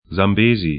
Pronunciation
Sambesi zam'be:zi Rio Zambeze 'ri:u zam'be:zə pt Fluss / stream 18°52'S, 36°17'E